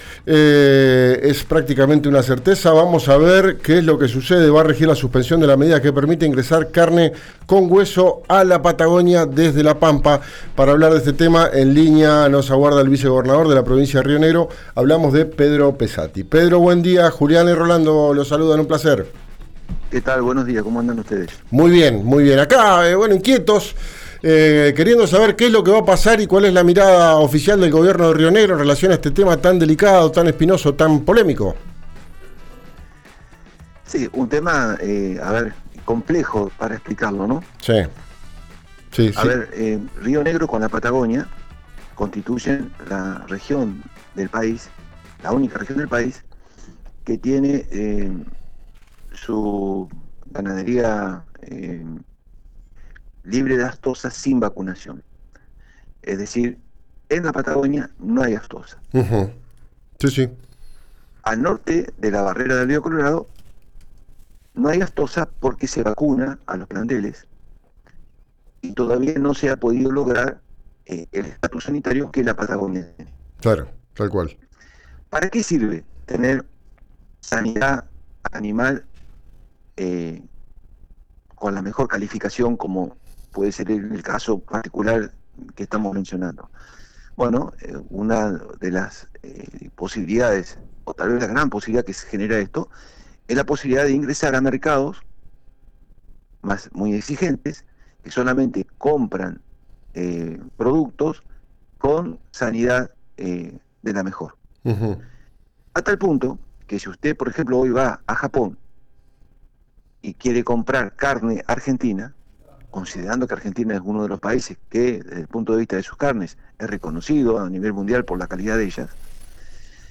El vicegobernador habló en RÍO NEGRO RADIO. Puso en duda que con la flexibilización de la barrera los precios bajen.
Escuchá al vicegobernador de Río Negro, Pedro Pesatti, en RÍO NEGRO RADIO